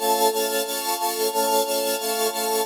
SaS_MovingPad02_90-A.wav